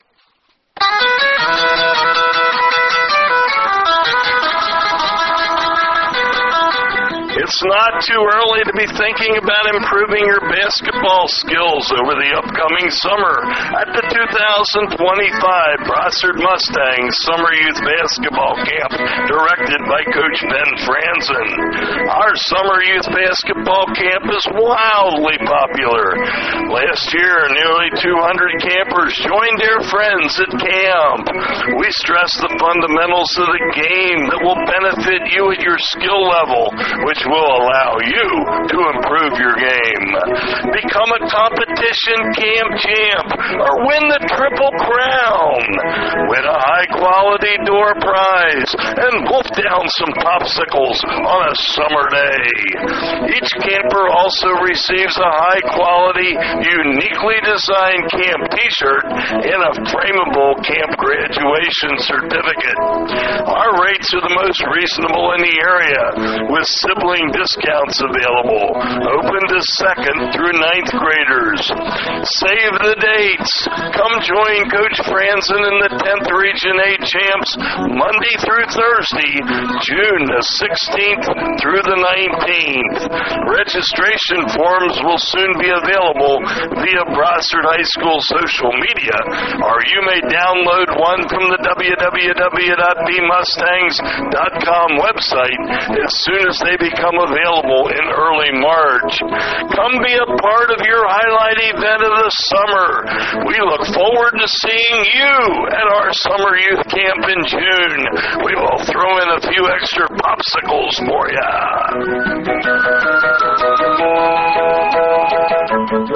2025-BBHS-Boys-Basketbal-Camp-Commercial.mp3